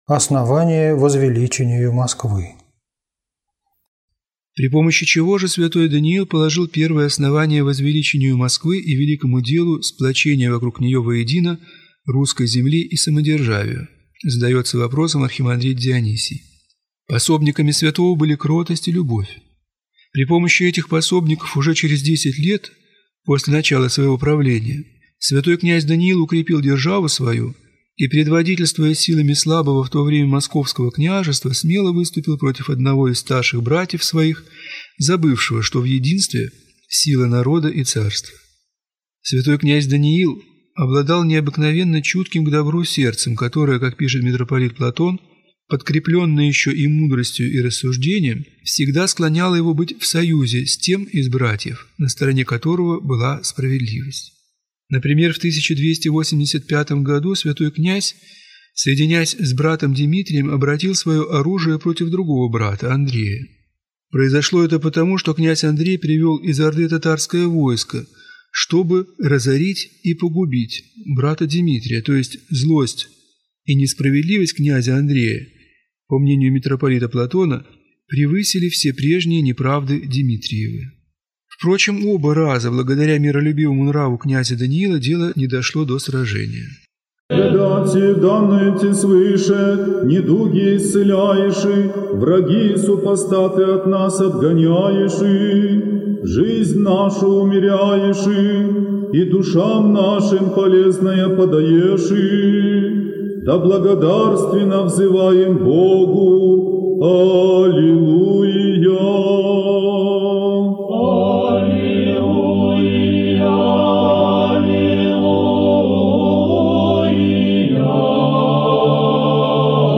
Аудиокнига Звезда пресветлая Земли Русской | Библиотека аудиокниг